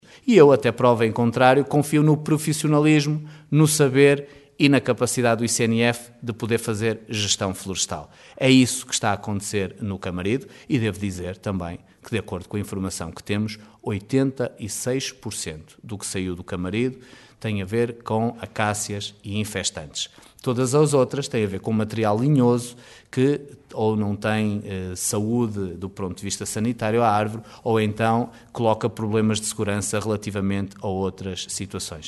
Opinião bem distinta manifestou o presidente da Câmara de Caminha, Miguel Alves que diz confiar no ICNF e nos seus profissionais, um organismo que considera ser bastante rigoroso.